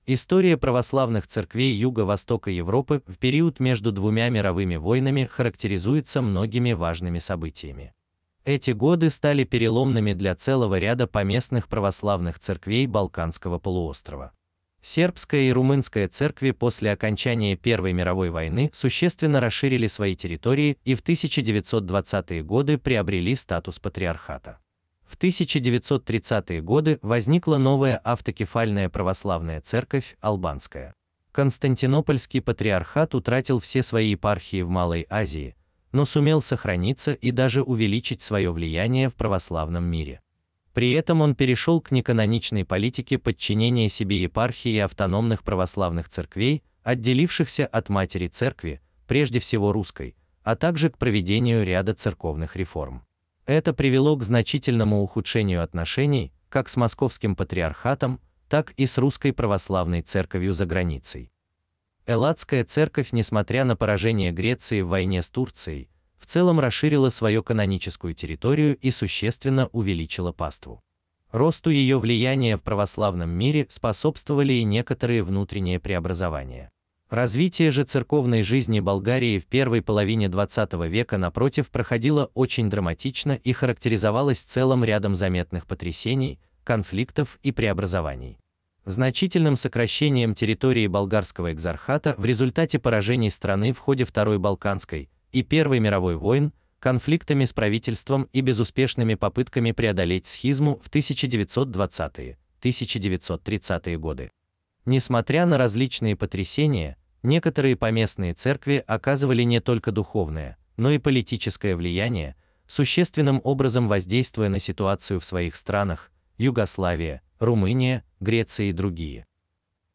Аудиокнига Православные церкви Юго-Восточной Европы между двумя мировыми войнами (1918 – 1939-е гг.)